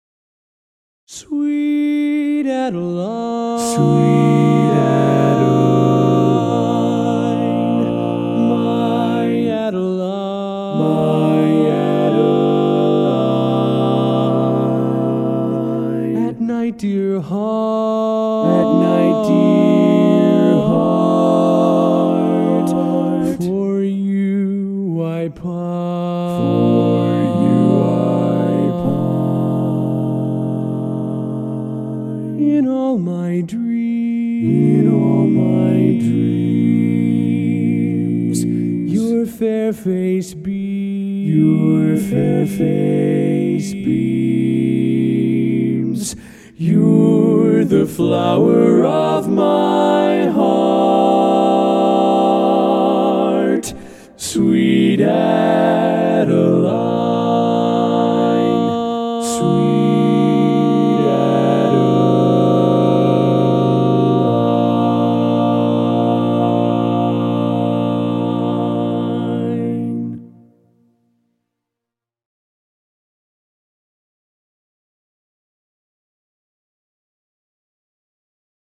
Barbershop
Full Mix